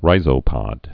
(rīzō-pŏd, -zə-)